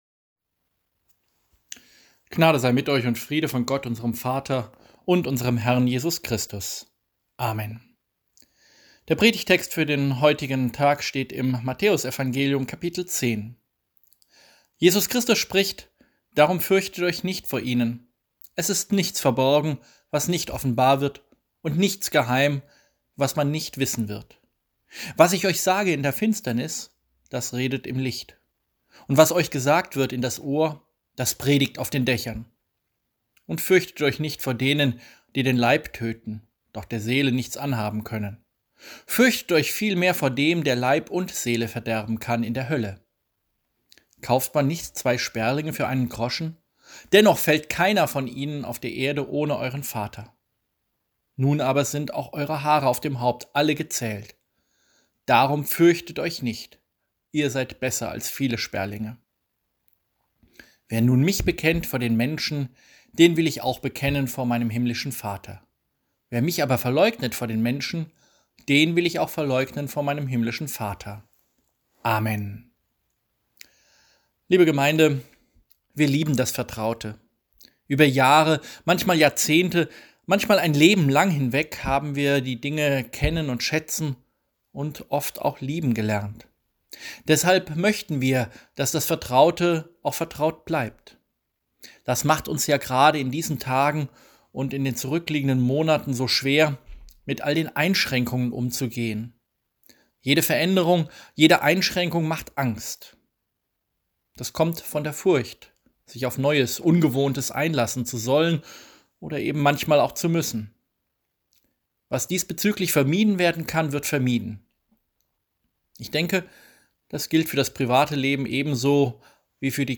Reformationspredigt.mp3